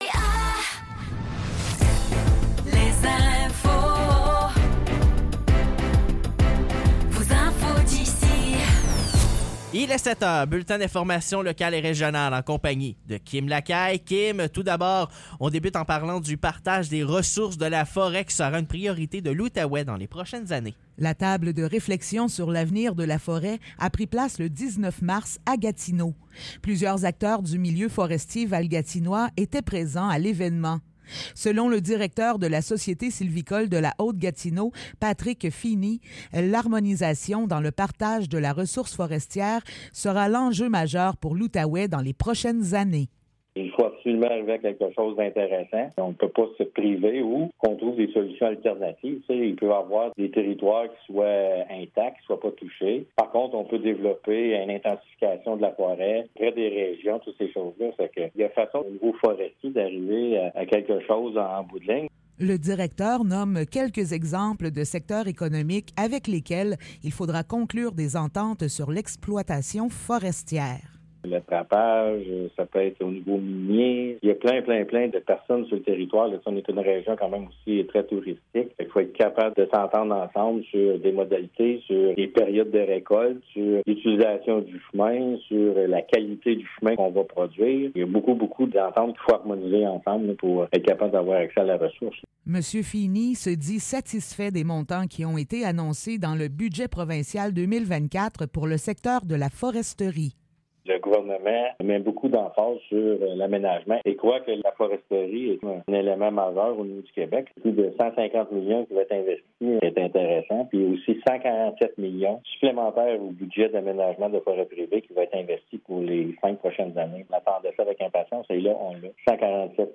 Nouvelles locales - 28 mars 2024 - 7 h